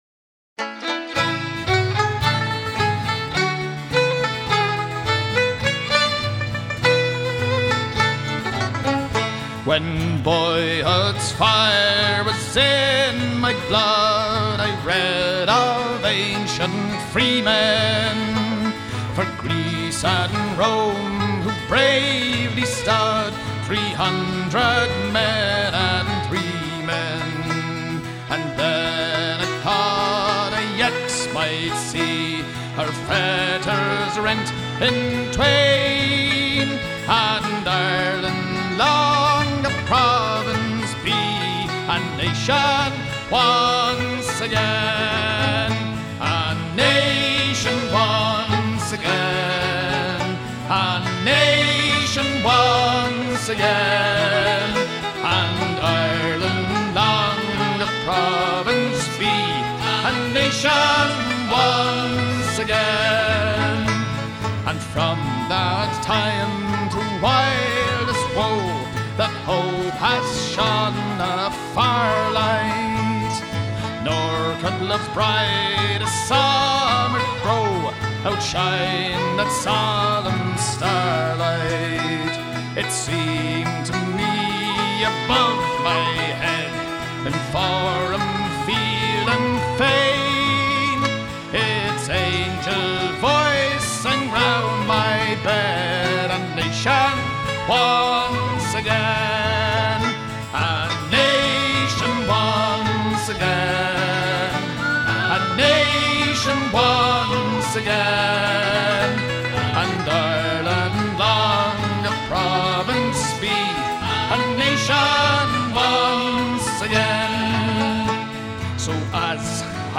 And so we come to what should have been my initial thought on how to celebrate St. Patrick’s Day – with real Irish music.